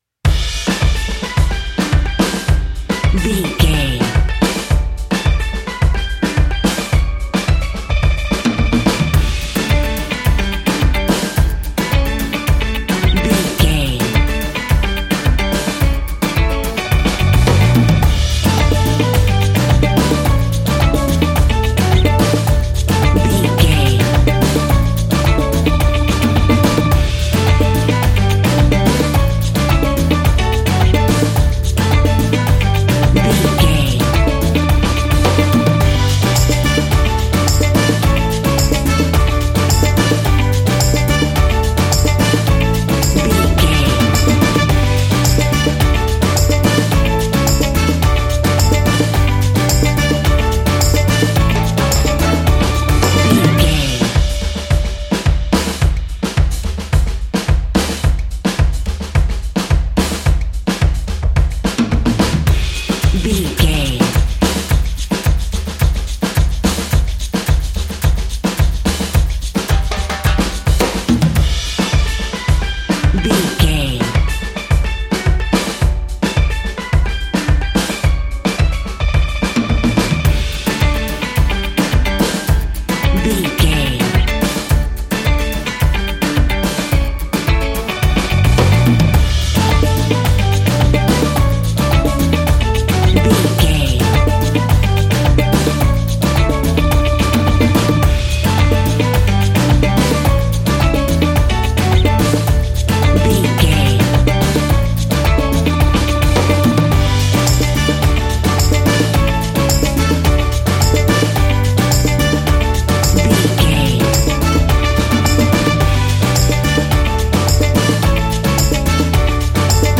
Ionian/Major
DOES THIS CLIP CONTAINS LYRICS OR HUMAN VOICE?
steelpan
drums
bass
brass
guitar